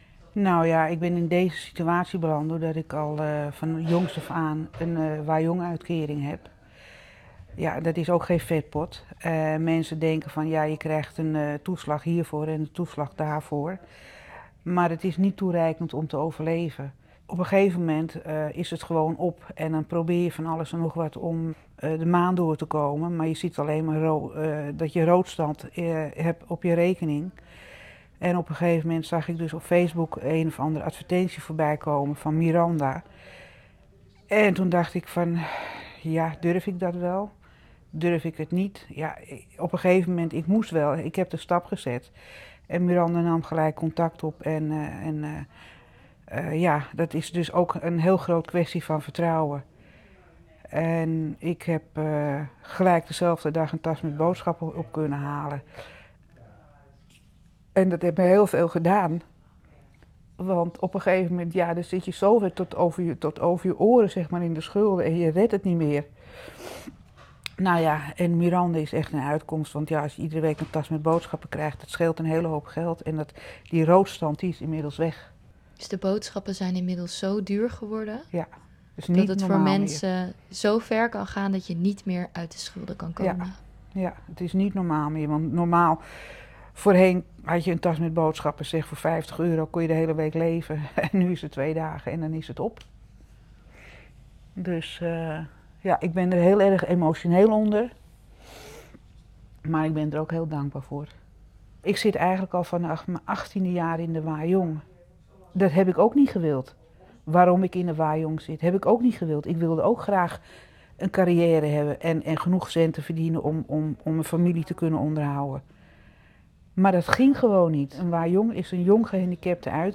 Zo deelt een van de ontvangers, anoniem, haar ervaring. Dankzij de wekelijkse steun van Prakkie Hoofddorp wist zij stap voor stap uit de schulden te komen.
Prakkie-intervi_Mono-1.wav